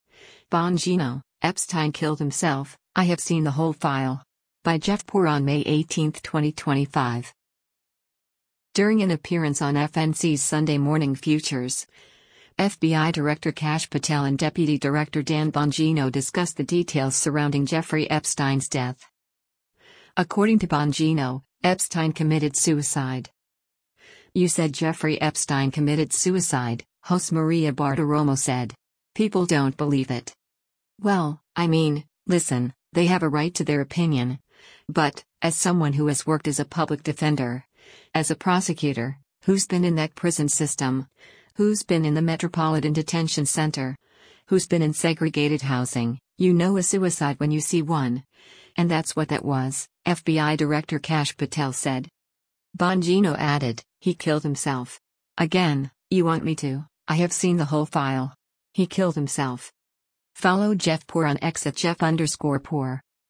During an appearance on FNC’s “Sunday Morning Futures,” FBI Director Kash Patel and Deputy Director Dan Bongino discussed the details surrounding Jeffrey Epstein’s death.
“You said Jeffrey Epstein committed suicide,” host Maria Bartiromo said.